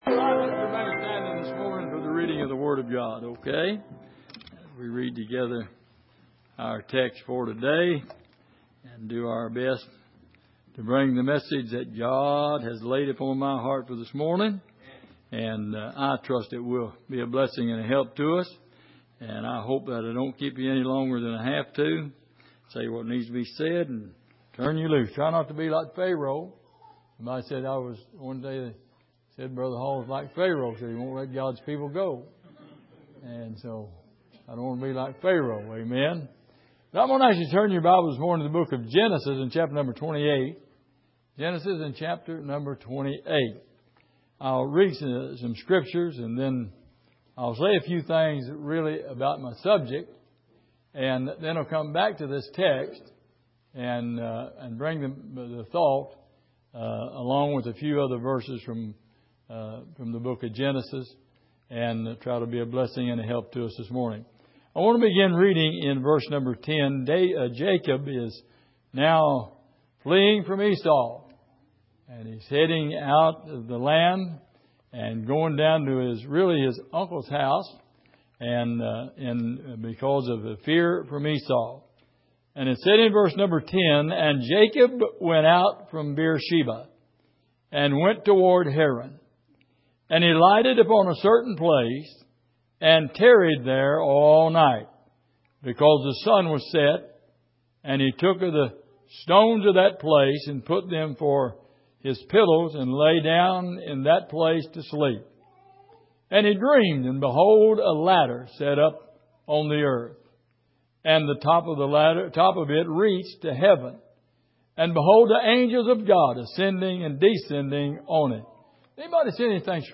Miscellaneous Passage: Genesis 28:10-22 Service: Sunday Morning The Blessings of Bethel « Why I Want To Continue On Who Caused It All?